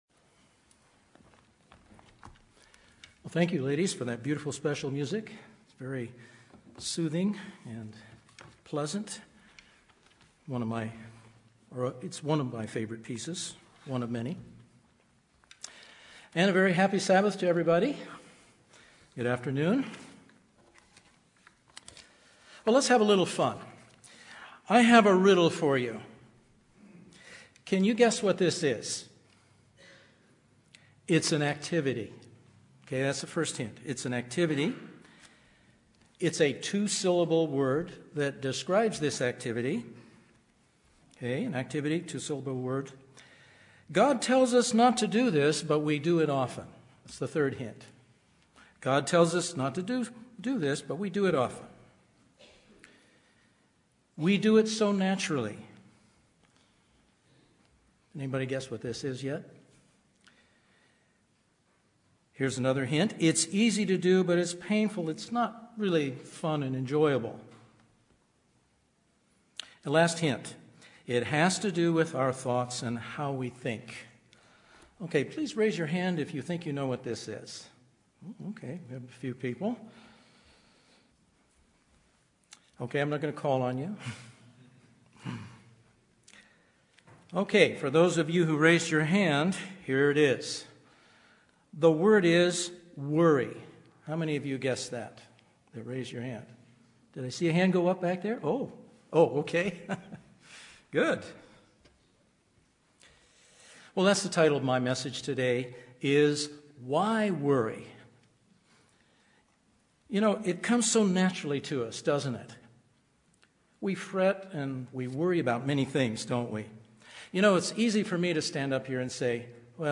UCG Sermon Studying the bible?
Given in Los Angeles, CA